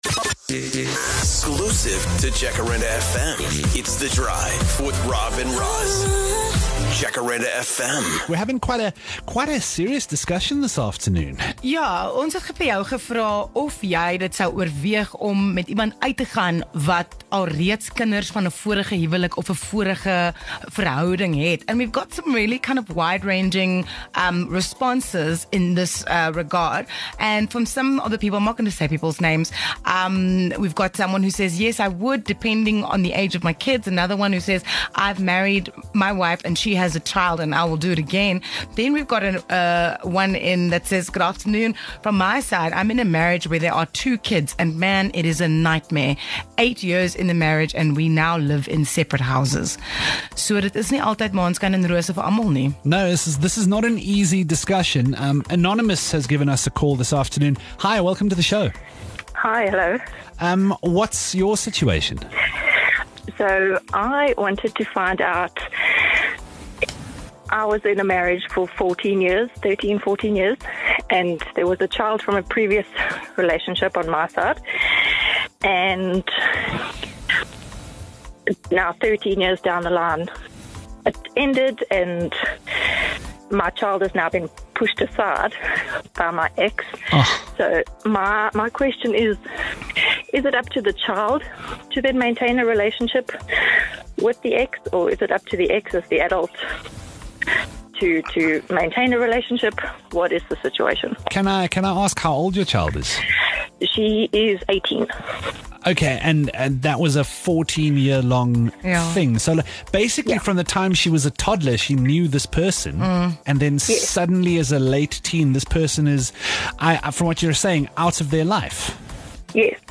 28 Feb Emotional anonymous caller shares story about her daughter's absent father
One listener decided to phone in and explain her current situation which involves her daughter and her previous partner.